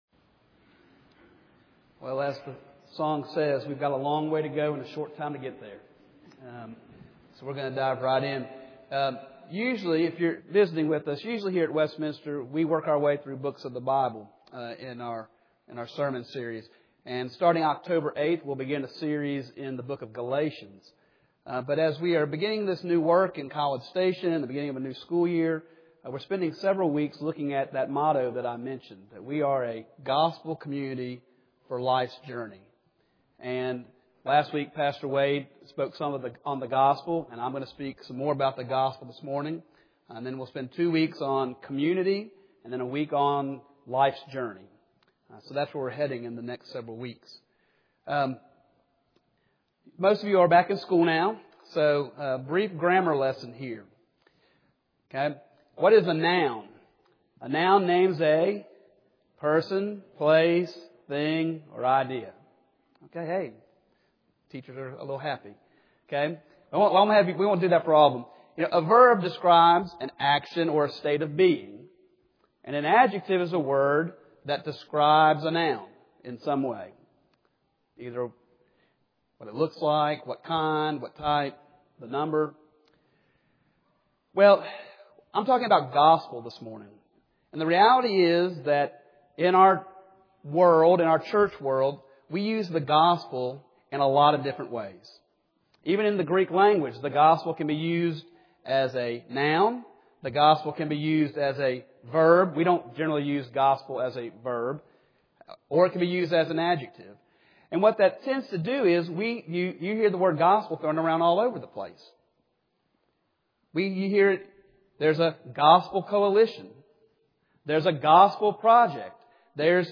Mark 10:45 Service Type: Sunday Morning